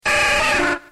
Cri de Carapuce dans Pokémon X et Y.